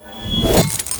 BSword2.wav